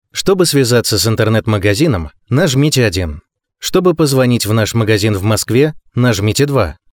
Муж, Автоответчик/Средний
Дикторская кабина, Lewitt LCT440 PURE, Audient iD4 MKII.